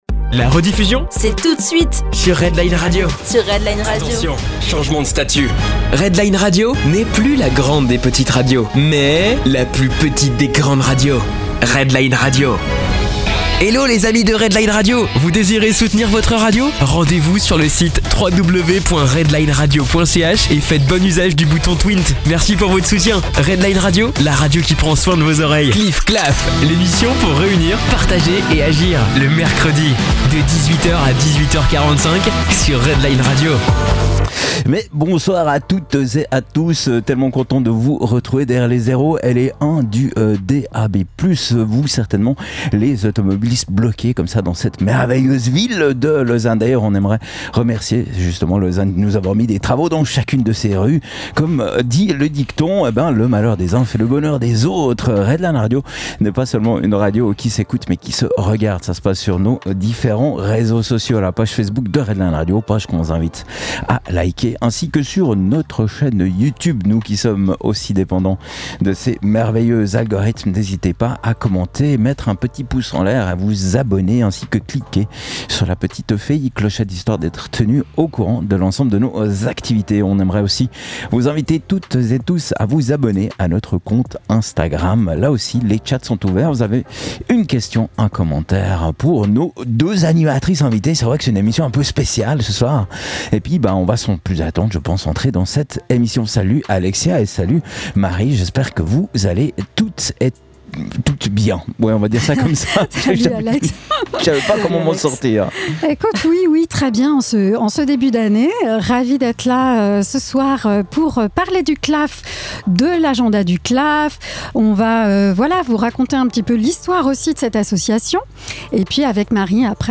Emission du 8 janvier 2025 sur Redline-Radio